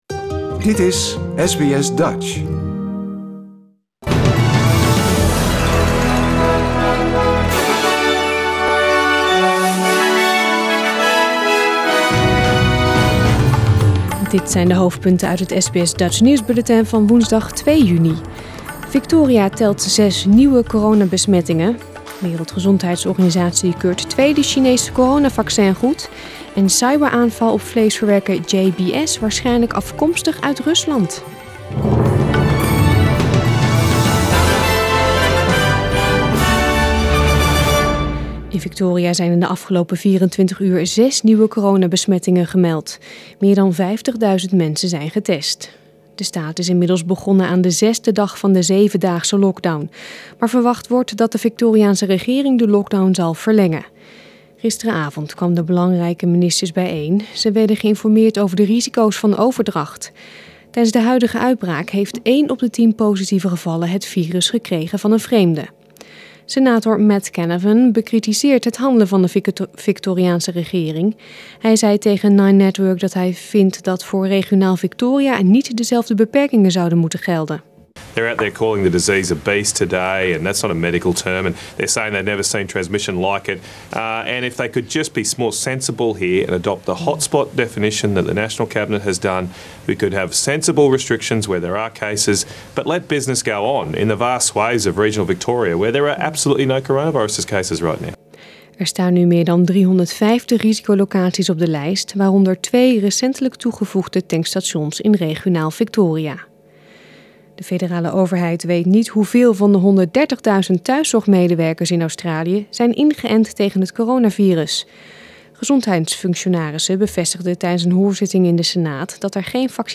Nederlands/Australisch SBS Dutch nieuwsbulletin van woensdag 2 juni 2021